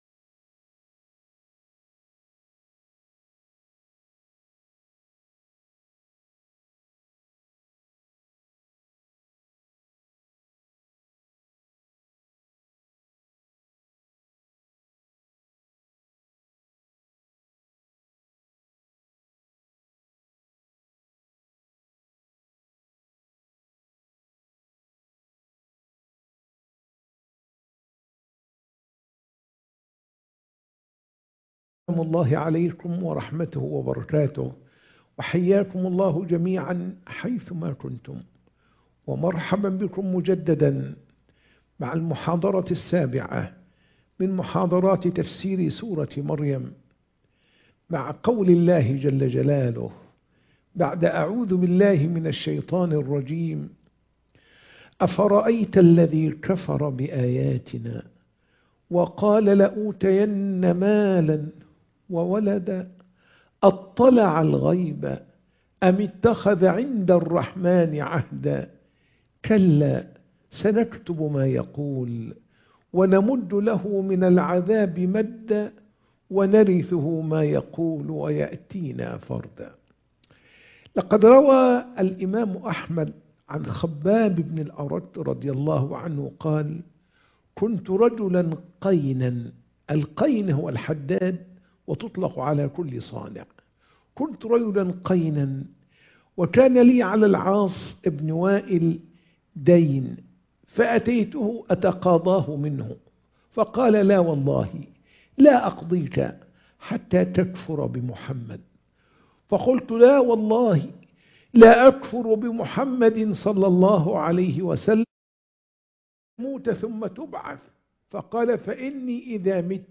محاضرة التفسير - سورة مريم - المحاضرة 7